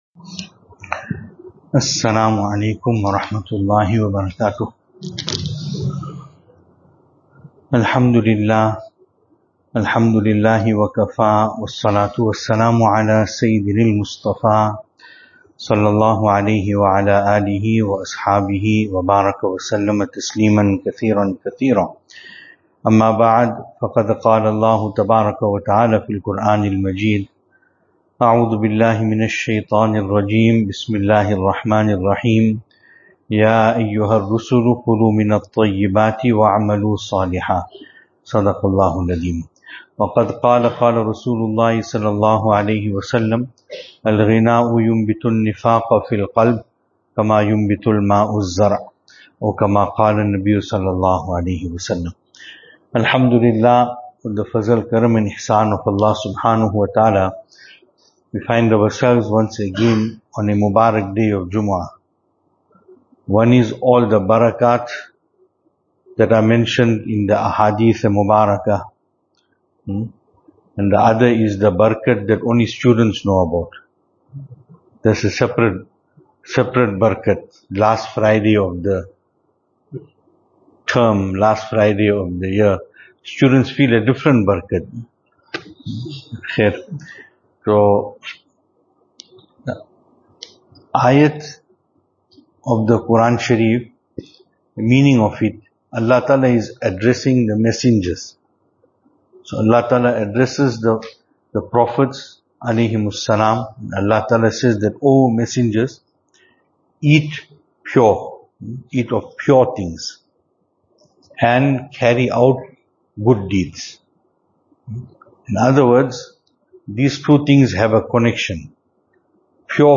Venue: Albert Falls , Madressa Isha'atul Haq
Service Type: Jumu'ah